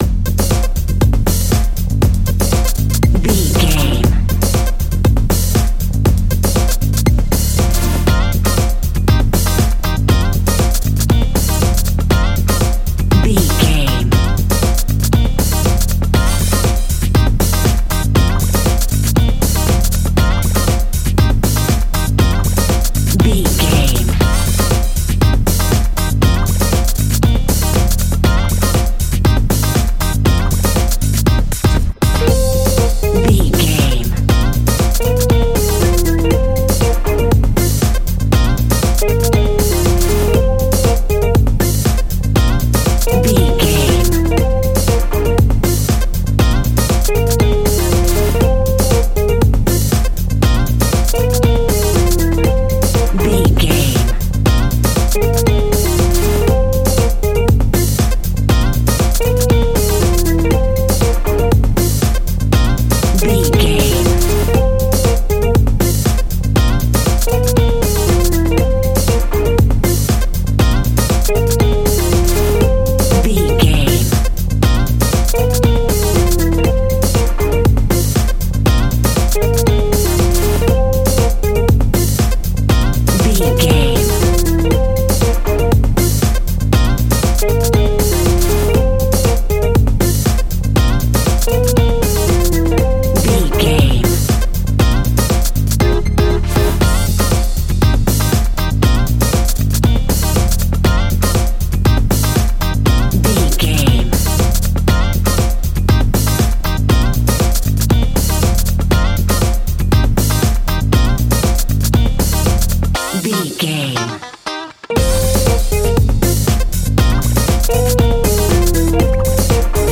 Aeolian/Minor
groovy
uplifting
driving
energetic
bass guitar
electric piano
synthesiser
electric guitar
drums
disco house
electro funk
upbeat
Synth Pads
clavinet
horns